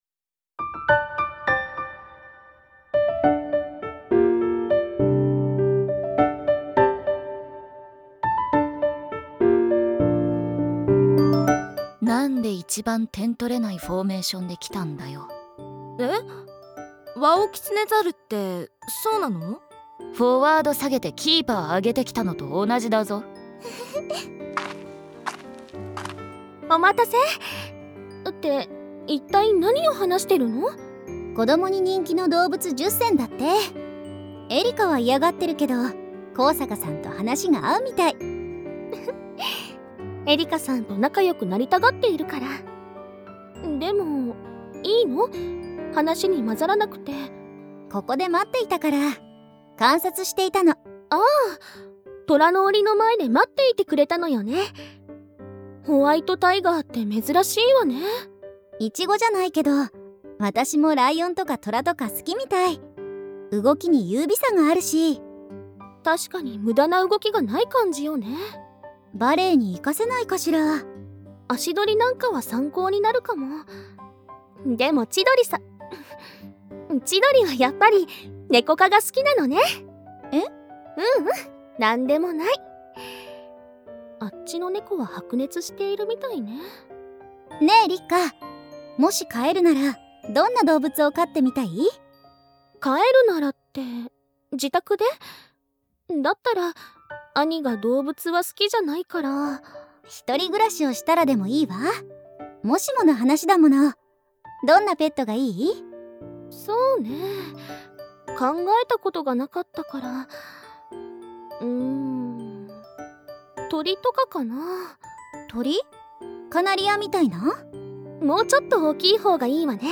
サンプルボイス2